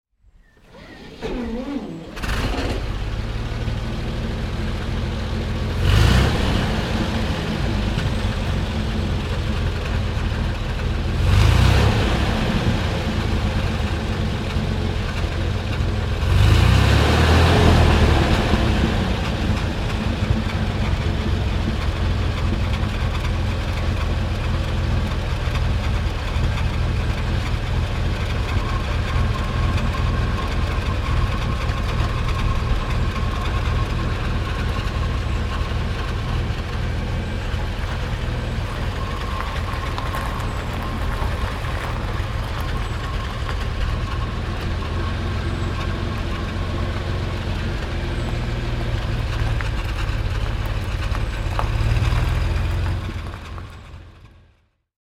Motorsounds und Tonaufnahmen zu Rolls-Royce Fahrzeugen (zufällige Auswahl)
Rolls-Royce Phantom I Ascot Tourer (1930) - Starten und Leerlauf